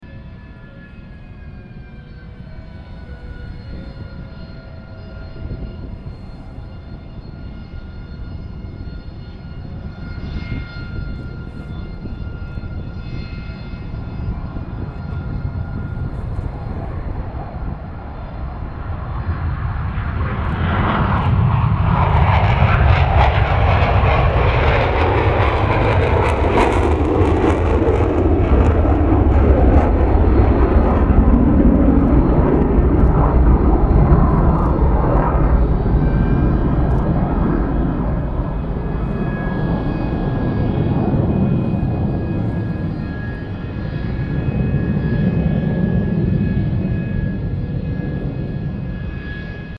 新田原基地航空祭 帰投篇
音を開くと、大きな音がします。ご注意ください！
右：F/A-18D　岩国基地
背景のカン高い音はハリアーのエンジン音です。